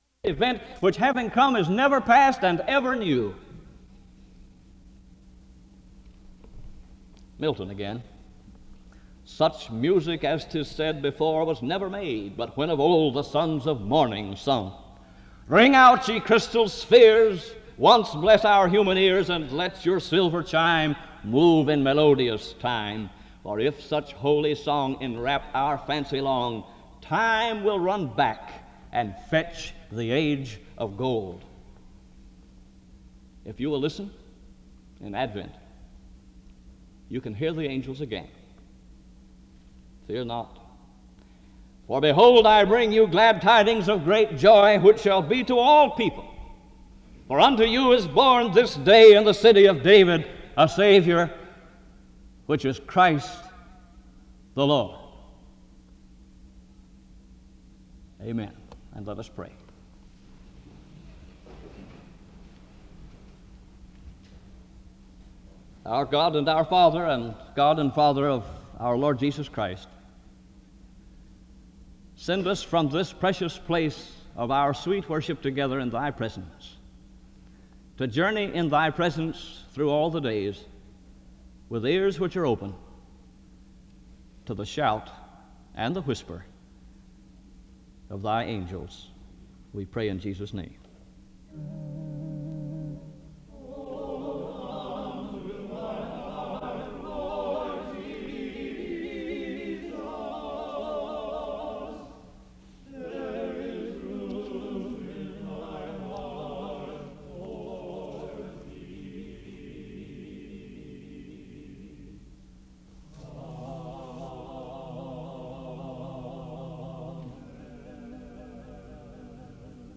SEBTS Chapel
• Wake Forest (N.C.)